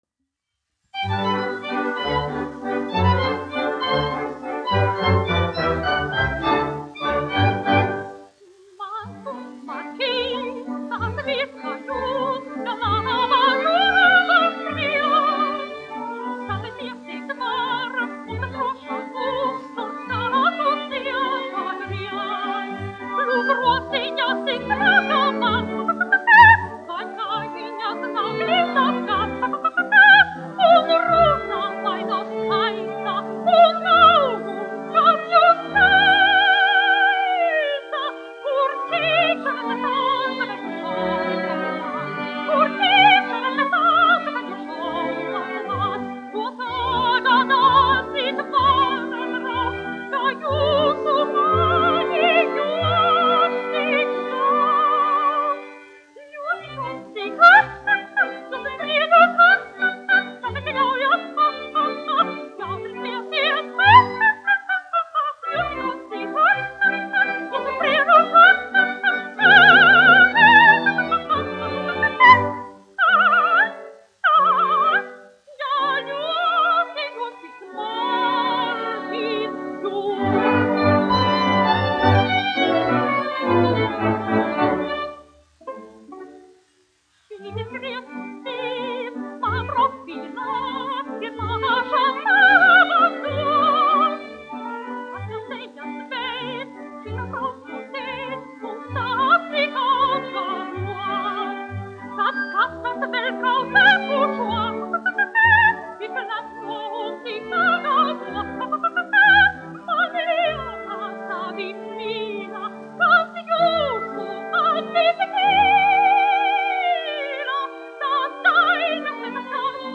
1 skpl. : analogs, 78 apgr/min, mono ; 25 cm
Operetes--Fragmenti